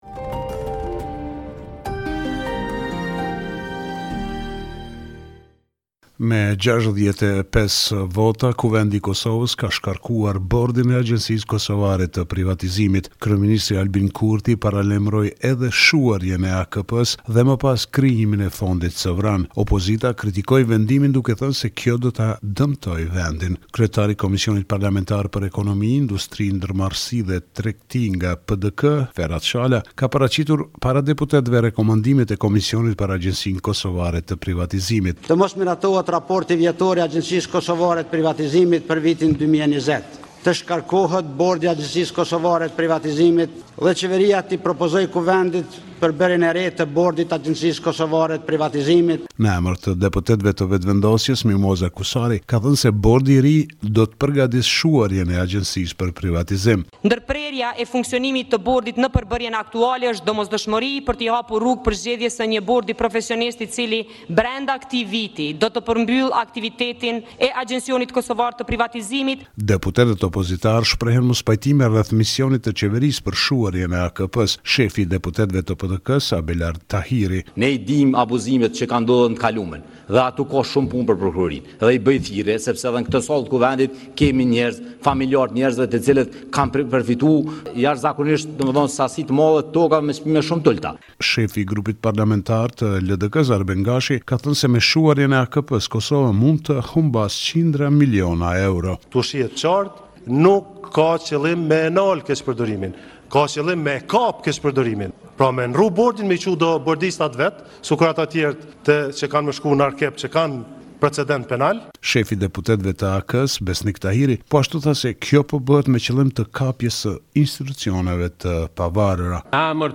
Raporti me te rejat me te fundit nga Kosova.